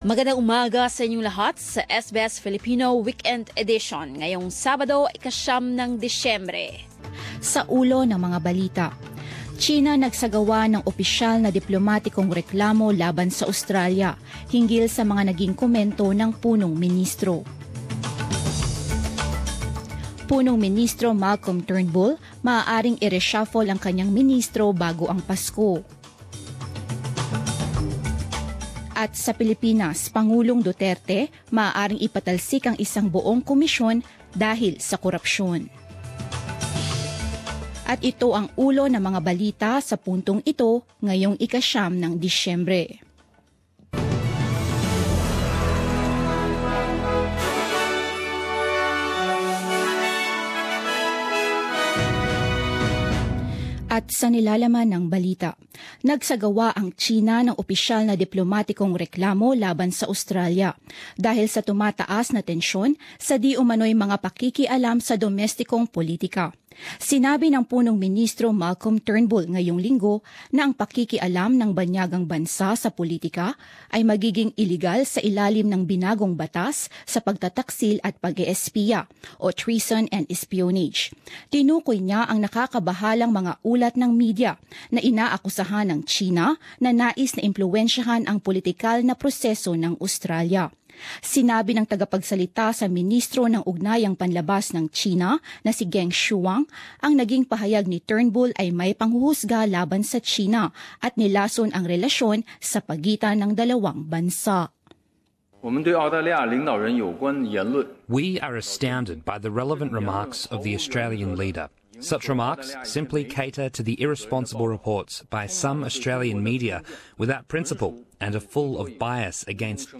Balita ng alas 10 ng umaga sa Wikang Filipino
Ika-sampu ng umagang balita sa Wikang Filipino 09 Disyembre 2017, Sabado